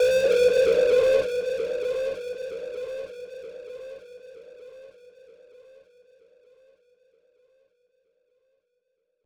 EN - Sizzle (FX).wav